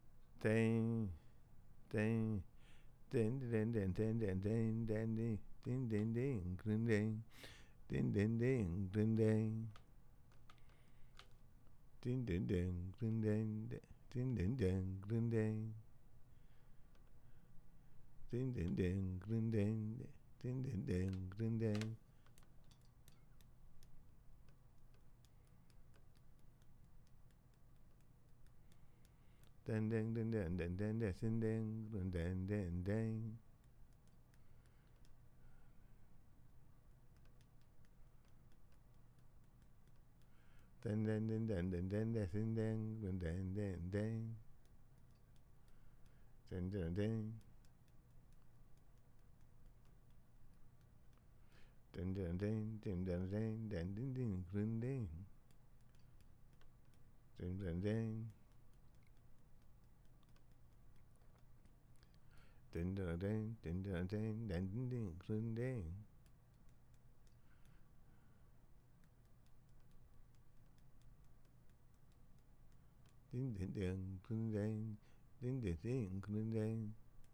African drumming Dagomba drumming
Talking drums